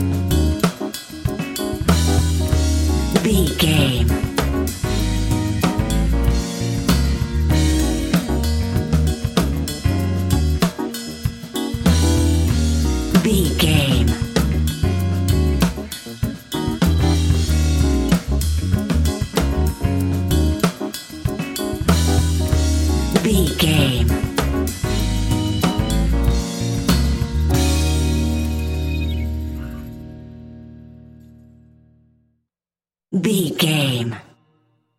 Fast paced
In-crescendo
Uplifting
Ionian/Major
D♯